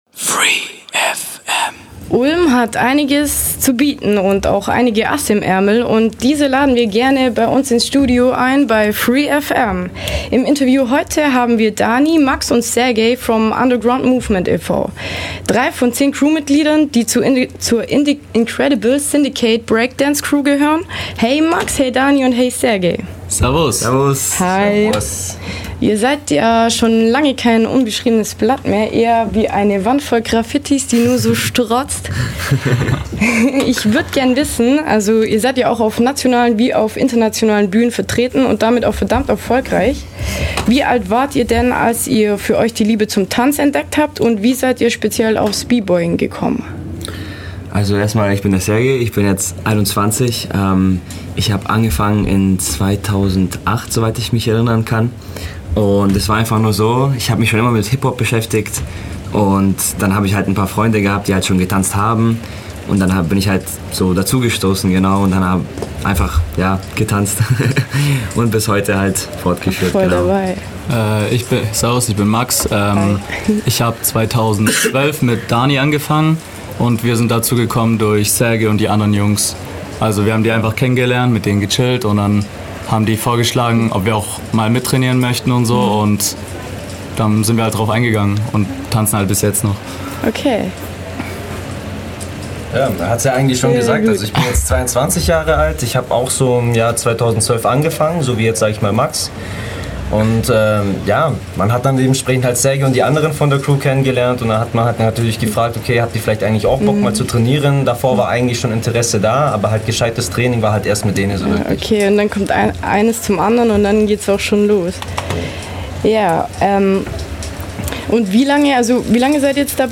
Ulm hat einige Asse im Ärmel und wir von free FM laden diese liebend gern ins Studio ein!